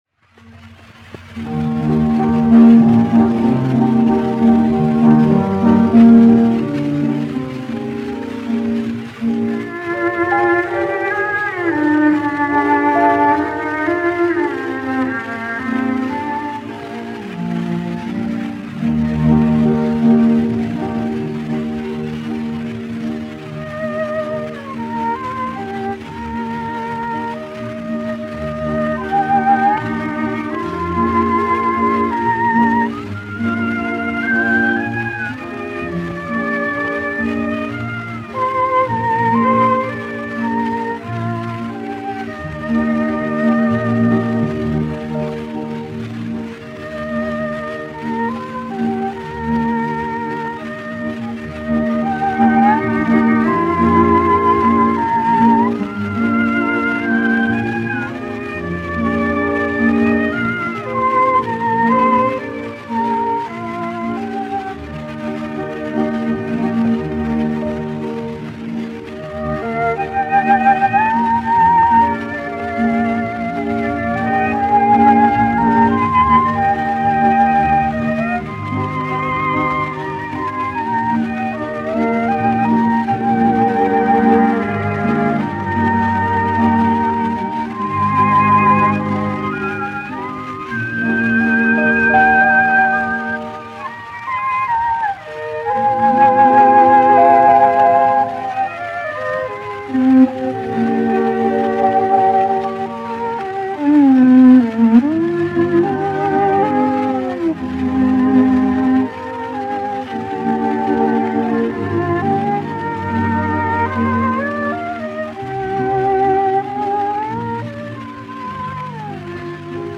1 skpl. : analogs, 78 apgr/min, mono ; 25 cm
Orķestra mūzika, aranžējumi
Latvijas vēsturiskie šellaka skaņuplašu ieraksti (Kolekcija)